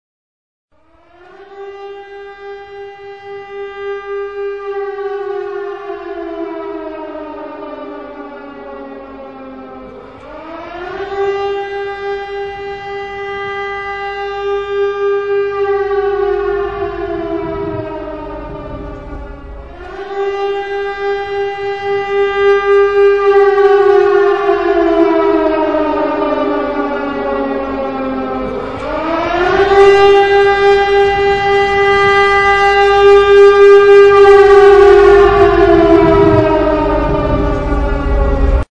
جلوه های صوتی